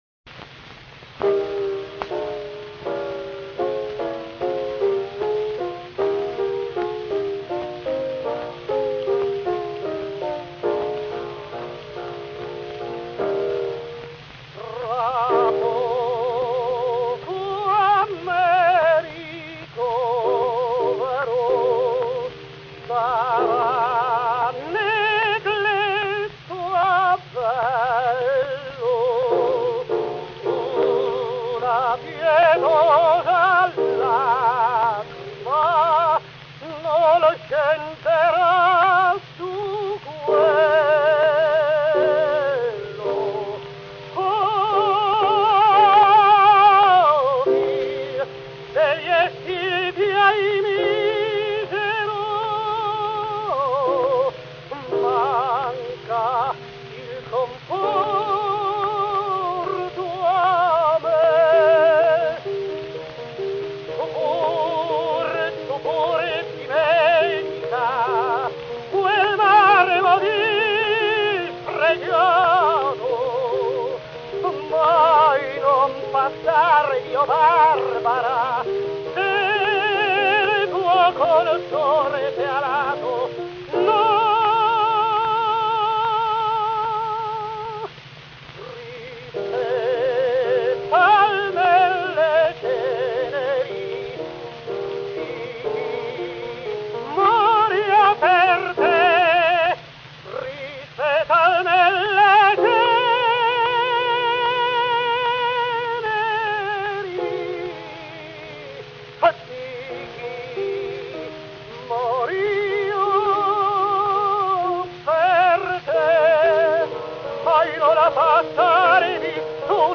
tenor
Gramophone, Milano, November 1902